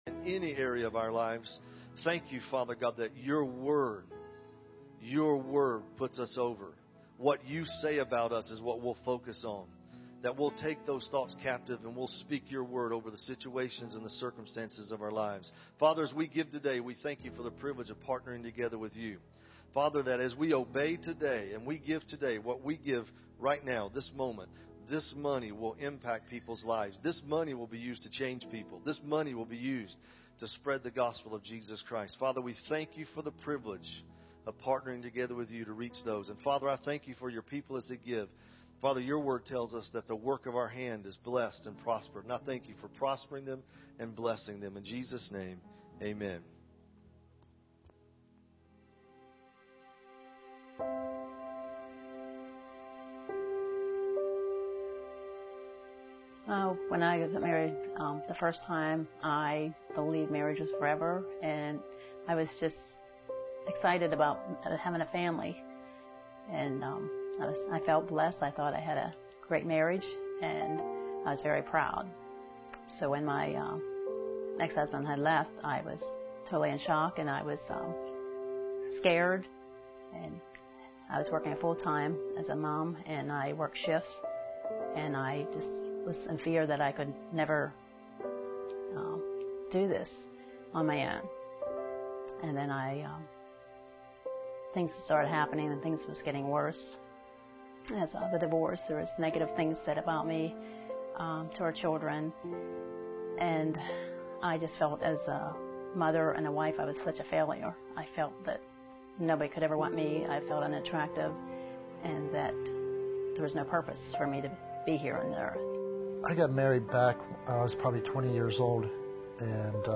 Access sermon video, audio, and notes from Victory Family Church online today!